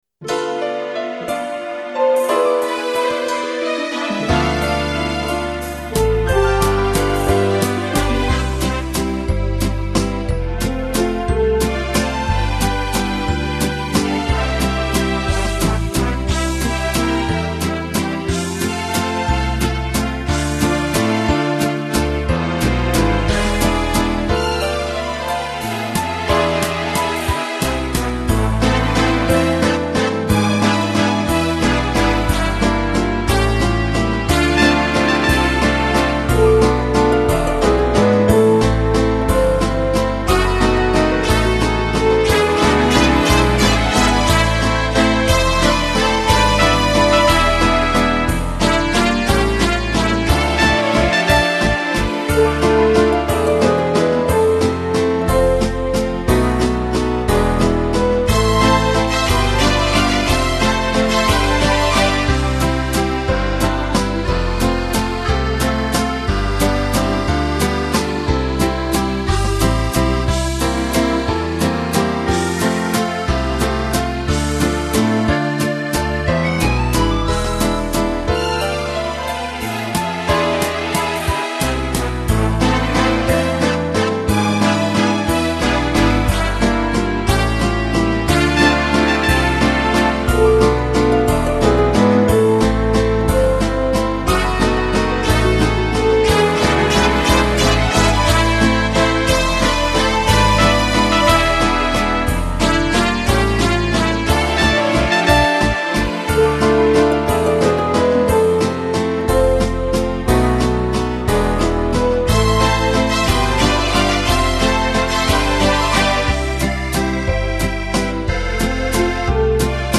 Слушать минус
караоке